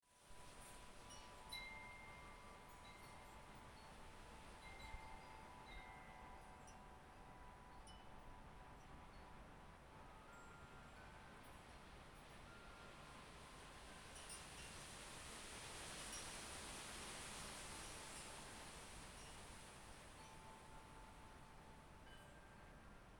…The sound of the wind and wind chimes last night on my porch while I was waiting on a storm to come: